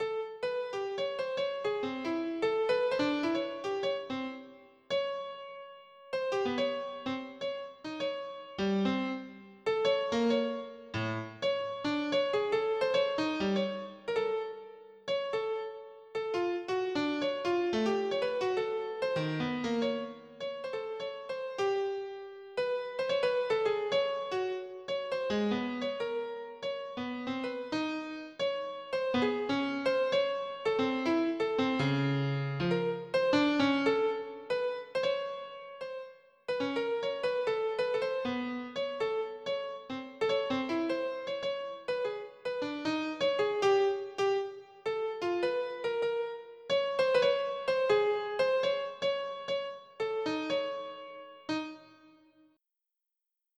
волна среднего прибоя 3.mid